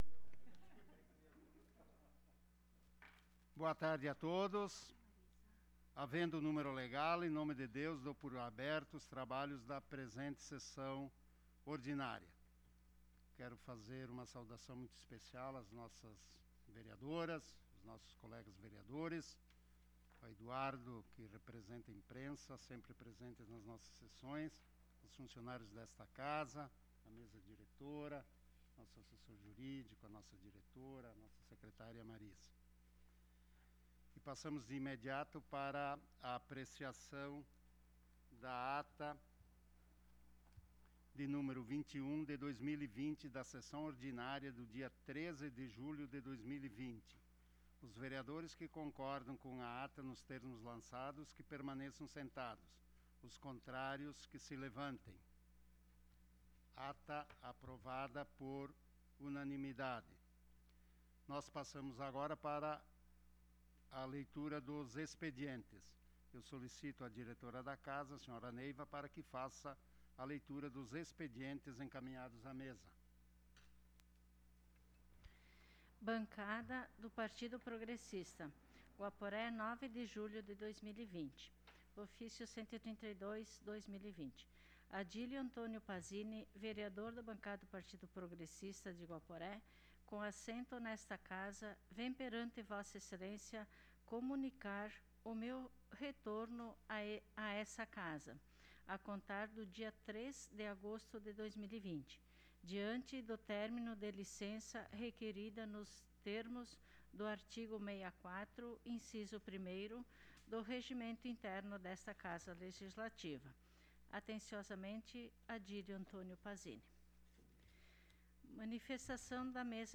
Sessão Ordinária do dia 20 de Julho de 2020